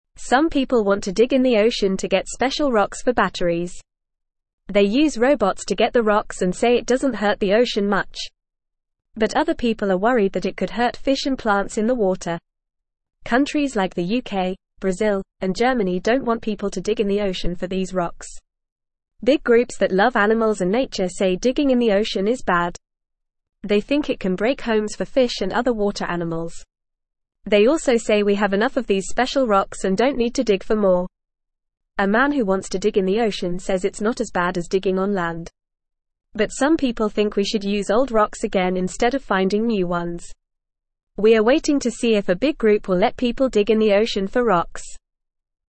Fast
English-Newsroom-Beginner-FAST-Reading-Digging-in-the-Ocean-Good-or-Bad.mp3